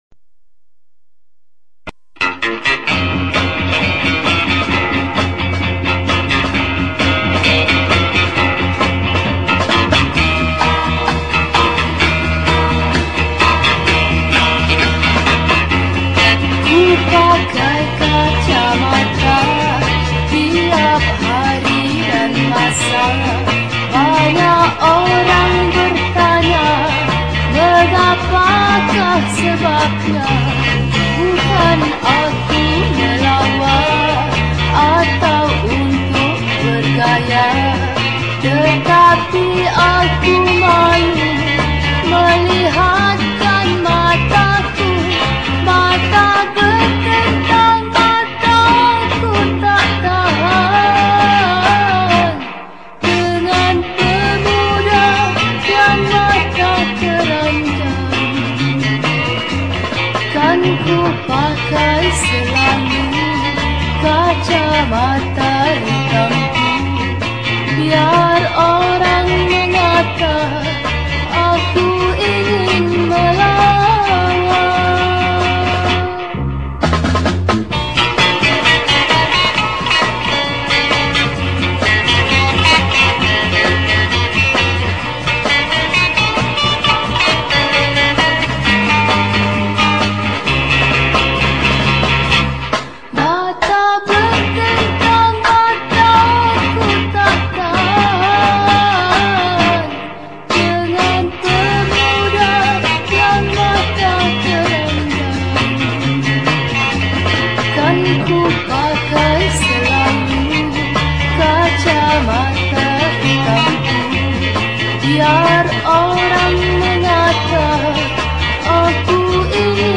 Malay Songs , Pop Yeh Yeh